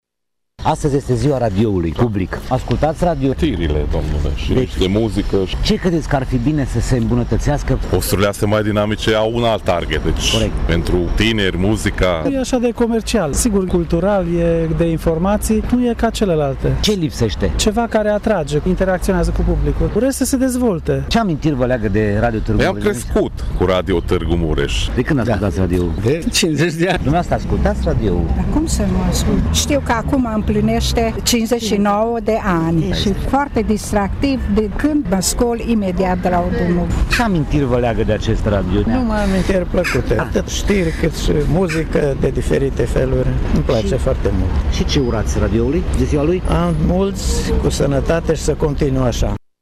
Am reușit să ne fidelizăm ascultătorii, iar unii dintre ei ne ascultă de ”o viață”: